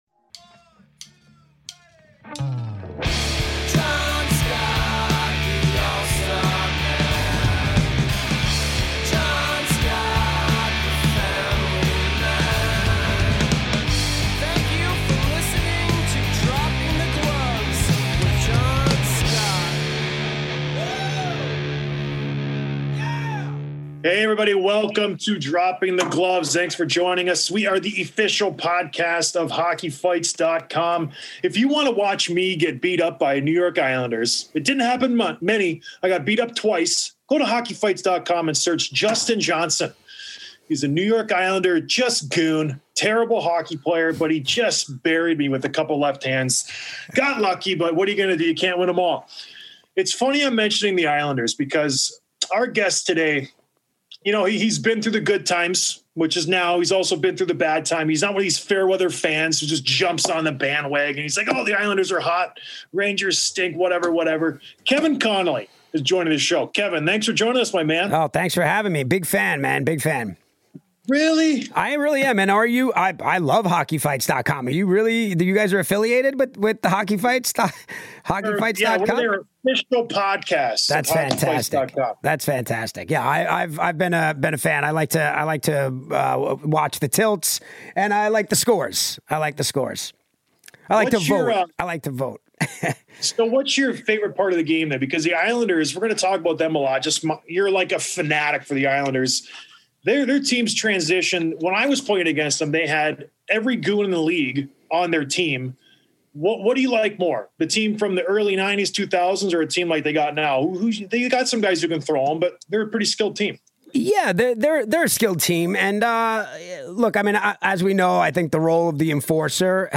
Interview with Actor/Producer Kevin Connolly